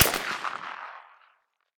med_crack_06.ogg